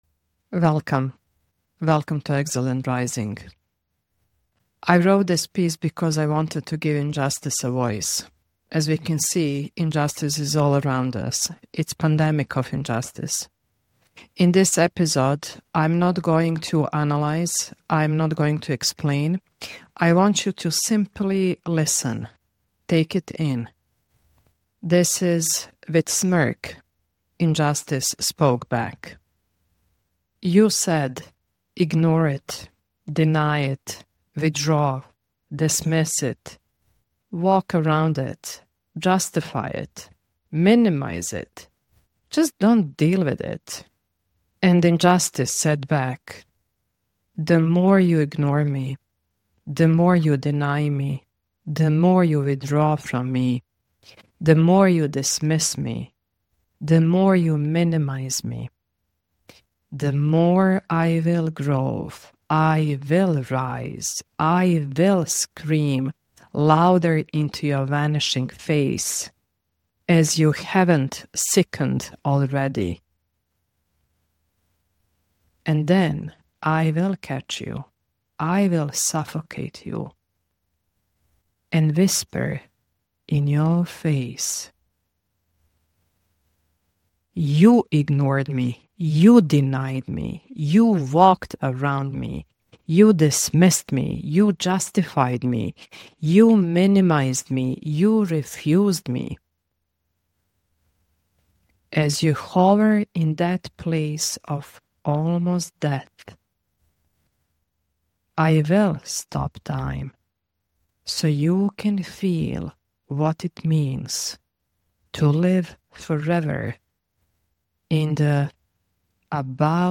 Injustice Trauma. Staying Blind to Injustice. Spoken Word.